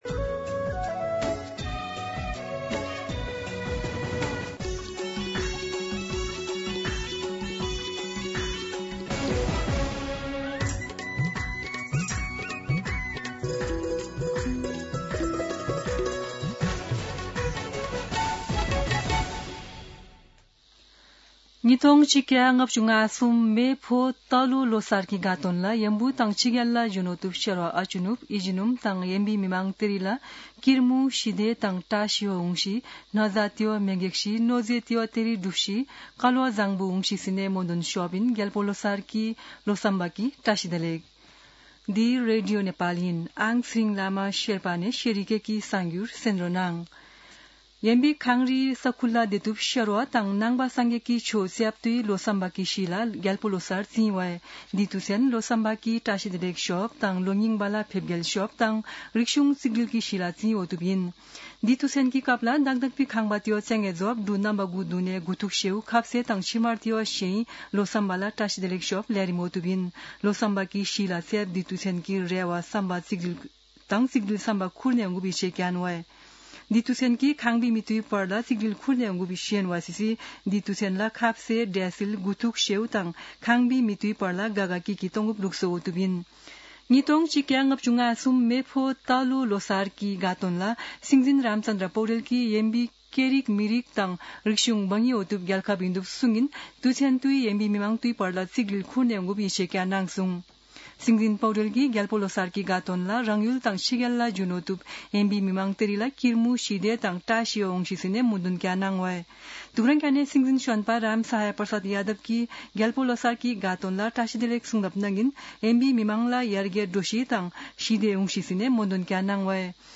शेर्पा भाषाको समाचार : ६ फागुन , २०८२
Sherpa-News-1.mp3